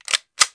WEAPON.mp3